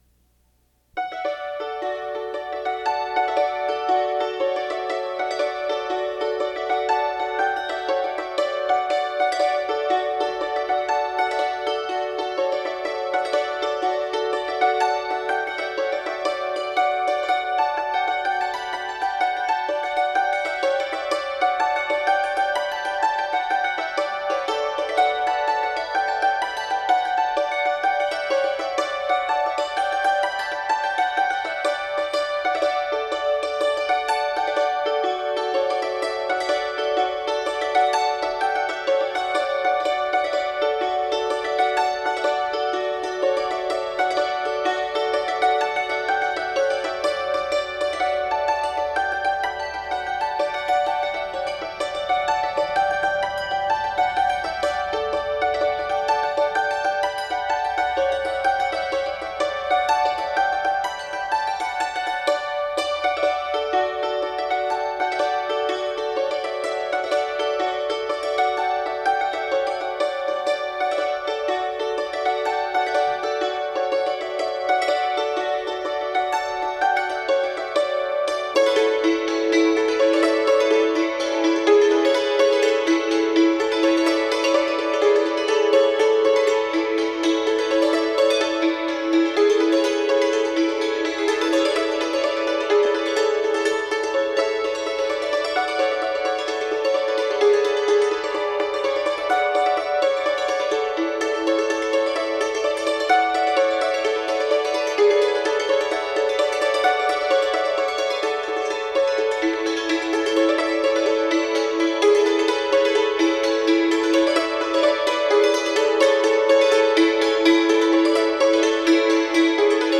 It was recorded in what was for many years the fair office.